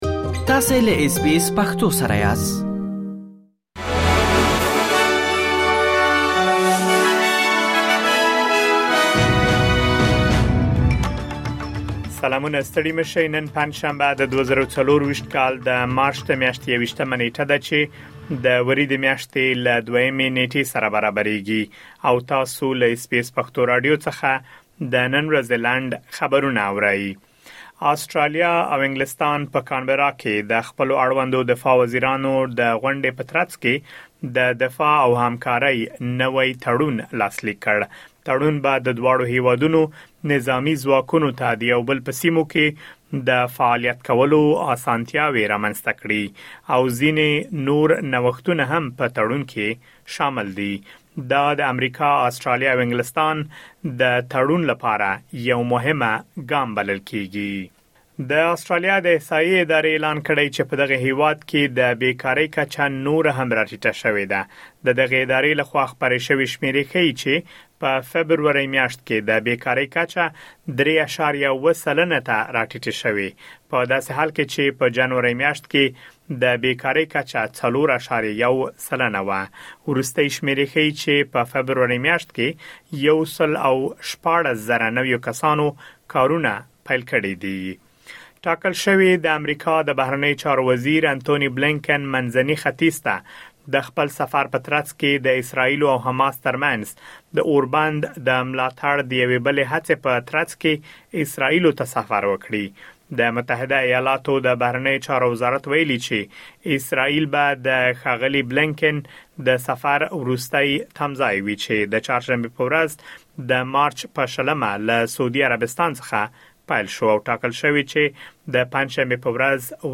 اس بي اس پښتو راډیو د نن ورځې لنډ خبرونه دلته واورئ.